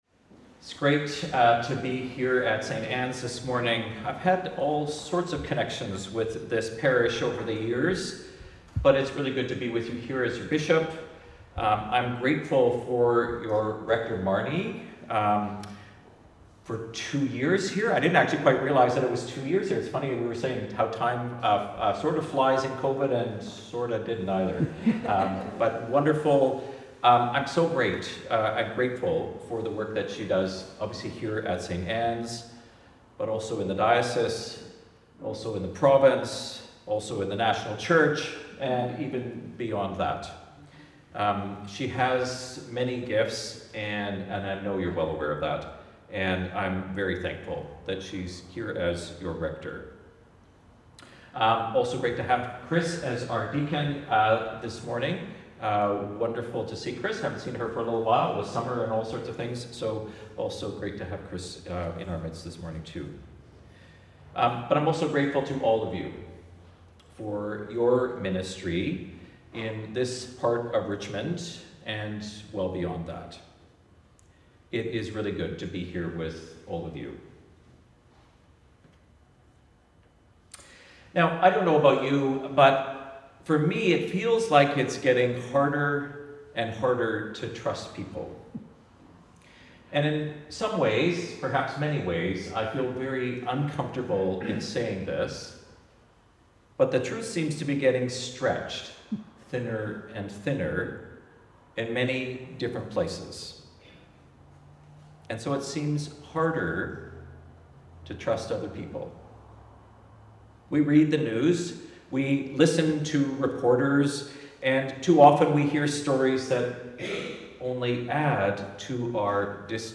Sermon preached by the Right Reverend John Stephens at St. Anne, Steveston, Celebration of the Eucharist for the Second Sunday in the Season of Creation and the 15th Sunday after Pentecost.